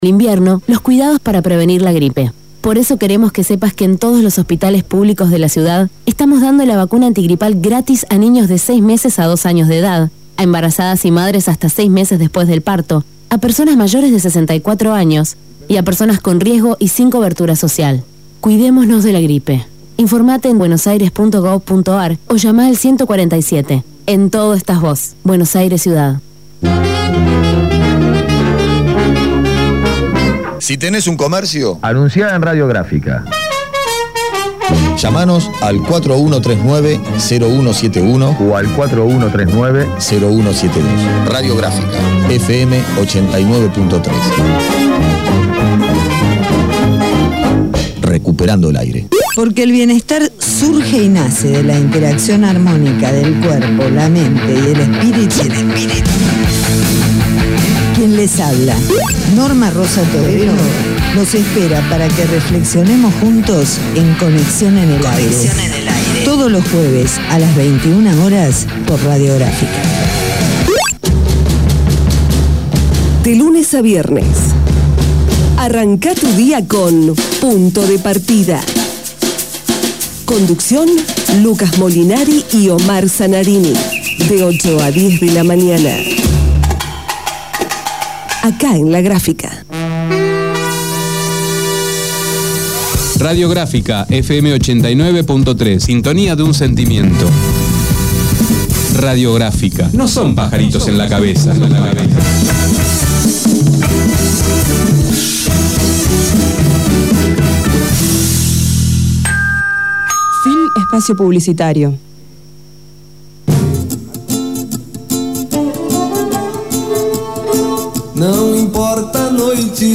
Duplex con Radio Pública de Guaira, Villarrica, Paraguay